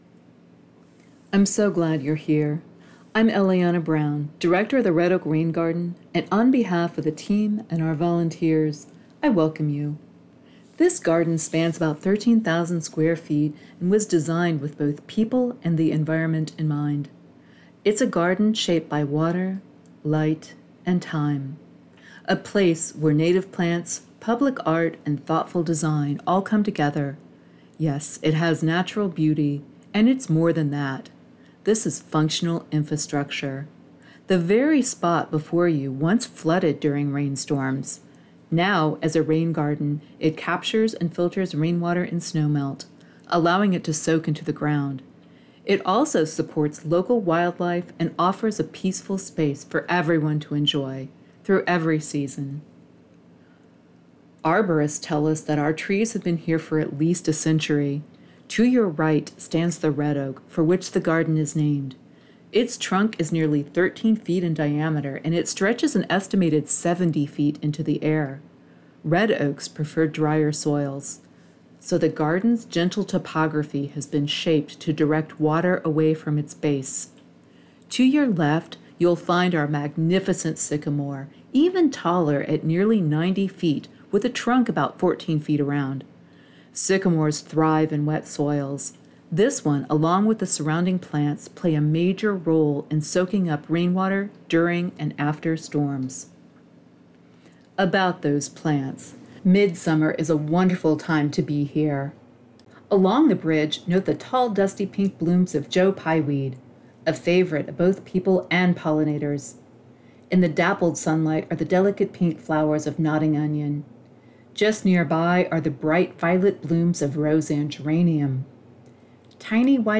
Audio Tour
rorg-audio-tour-midsummer.mp3